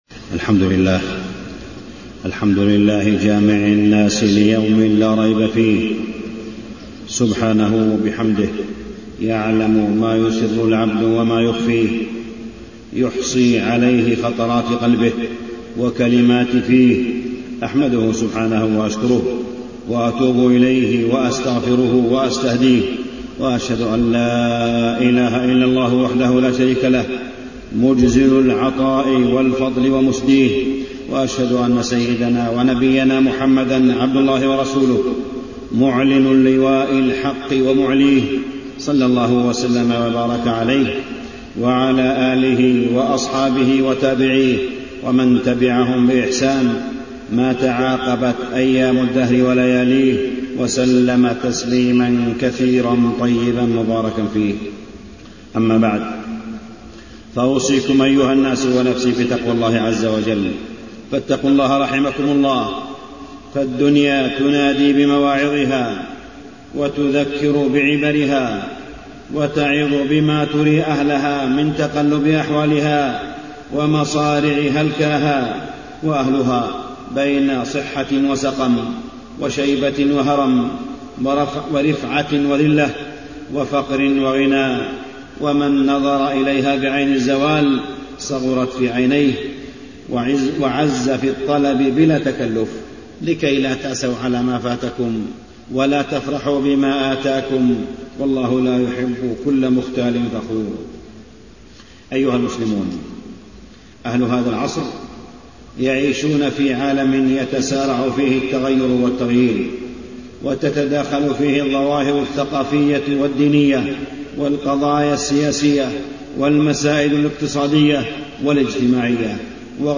تاريخ النشر ١٦ ذو القعدة ١٤٢٩ هـ المكان: المسجد الحرام الشيخ: معالي الشيخ أ.د. صالح بن عبدالله بن حميد معالي الشيخ أ.د. صالح بن عبدالله بن حميد الحوار بين الأمم